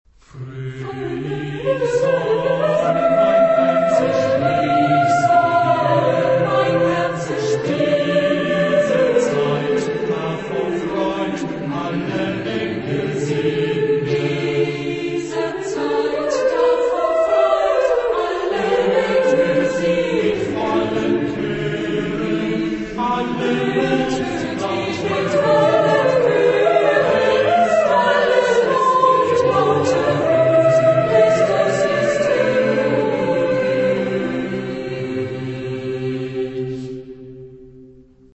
Genre-Style-Forme : Sacré
Type de choeur : SATB  (4 voix mixtes )
Tonalité : do majeur